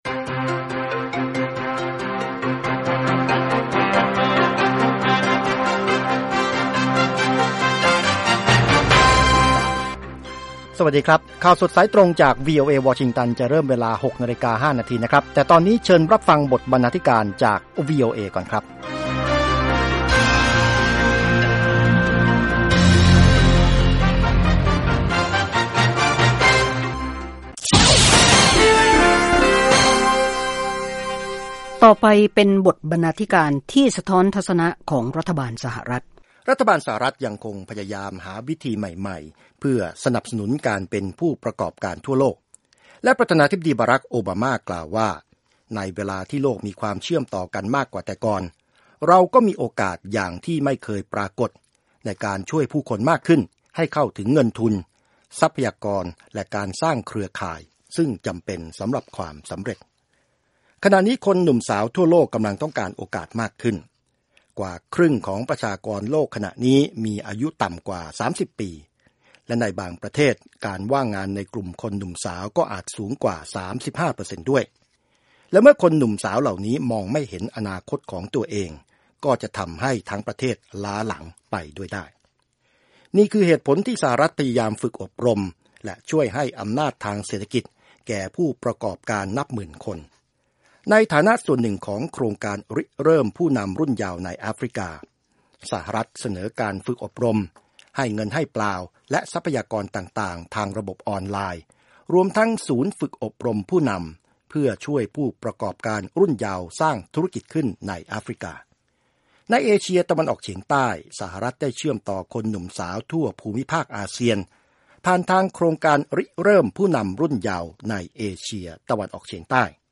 ข่าวสดสายตรงจากวีโอเอ ภาคภาษาไทย 6:00 – 6:30 น. วันพฤหัสบดี ที่ 21 พฤษภาคม 2558